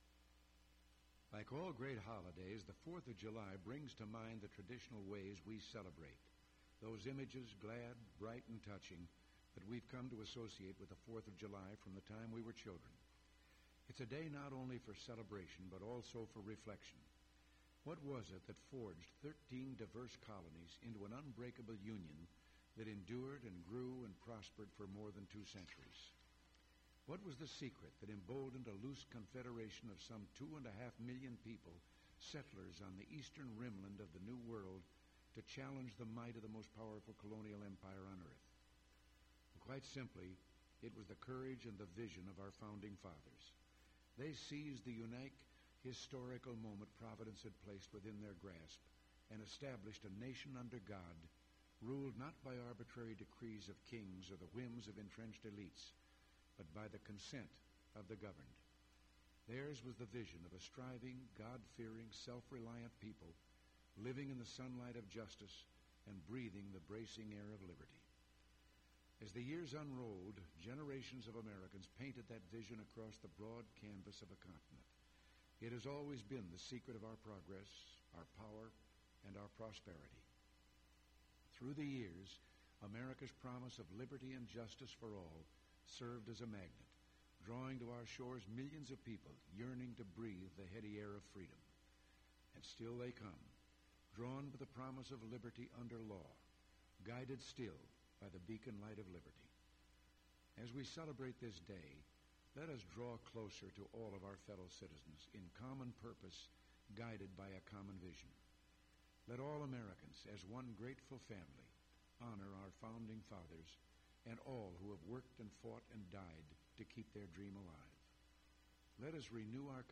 President Reagan’s Generic July 4 Message from the oval office
MP3 Audio file Tape Number WHMEDIA AUD-6 Side 1 Date 07/04/1987 Time Counter Reading 02:30 Personal Reference Ronald Reagan Collection Reference WHMEDIA Geographic Reference Washington, DC